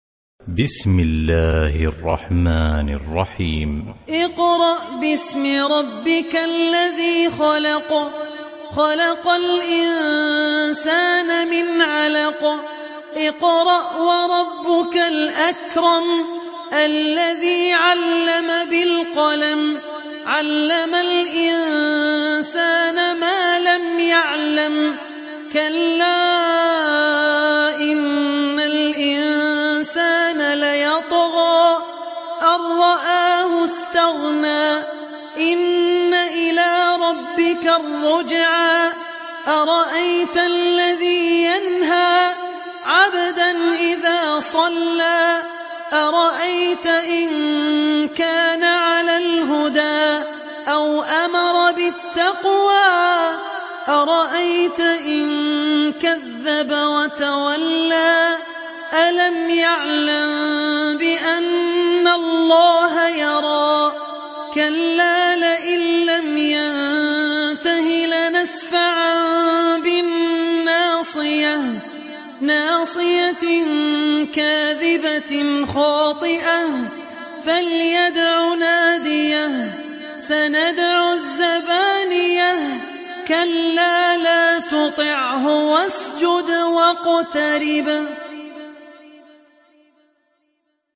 Sourate Al Alaq Télécharger mp3 Abdul Rahman Al Ossi Riwayat Hafs an Assim, Téléchargez le Coran et écoutez les liens directs complets mp3
Moratal